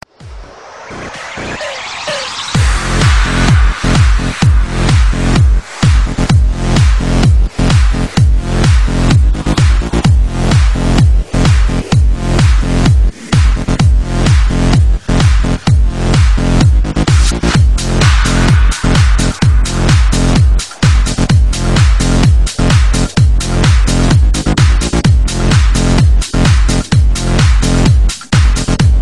Electronica
electronica , trance , club , tecno ,